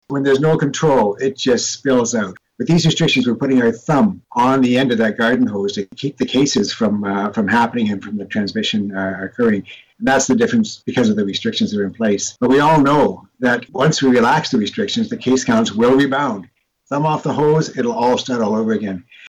During his weekly media briefing Dr. Ian Gemmill noted that the local COVID-19 cases numbers in that area have been dropping substantially and that cases of the flu nationwide are minimal as well.